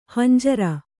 ♪ hanjara